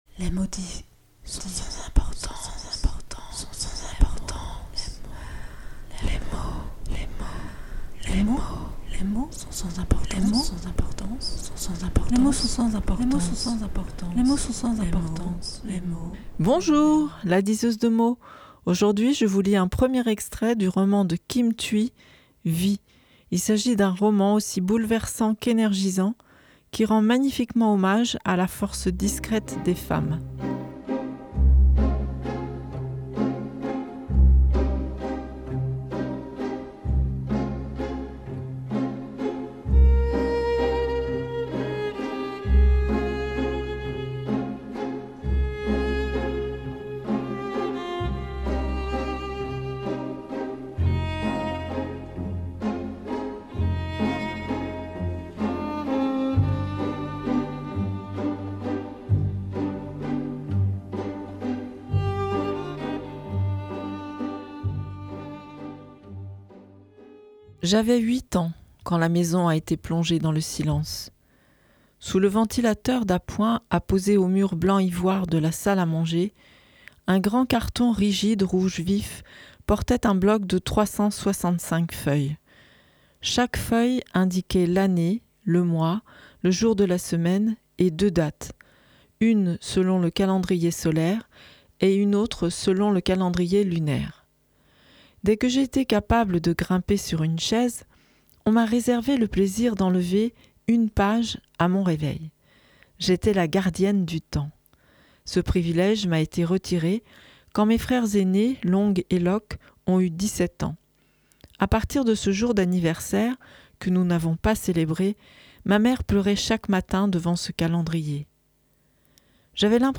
1er Extrait de Vi de Kim Thúy